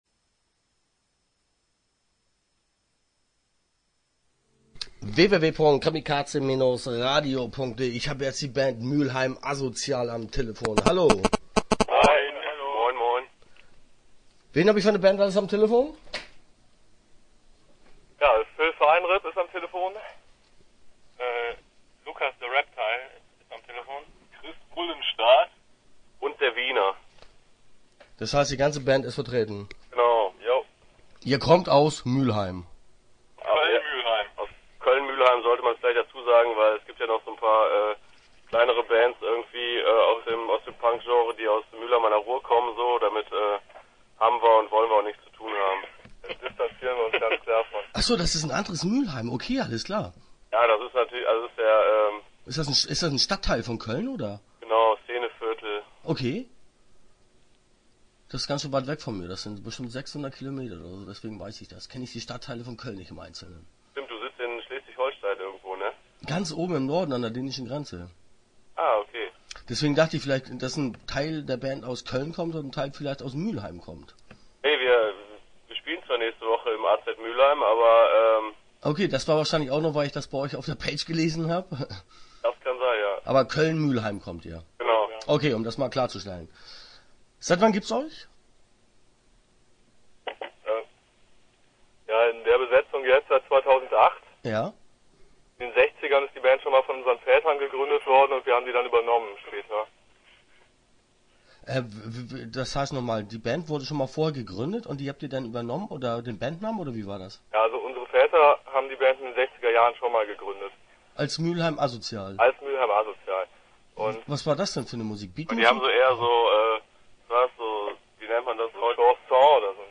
Start » Interviews » Mülheim Asozial